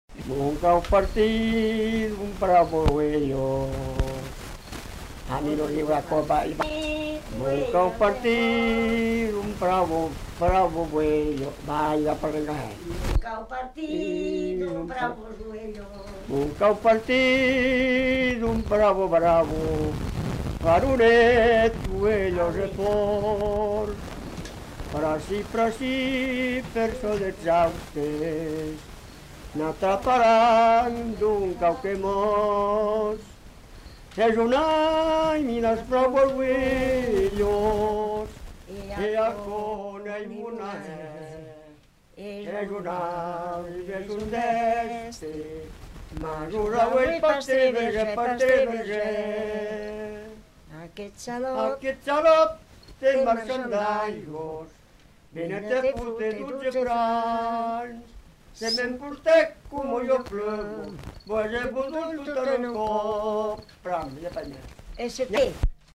Aire culturelle : Savès
Lieu : Puylausic
Genre : chant
Effectif : 2
Type de voix : voix d'homme ; voix de femme
Production du son : chanté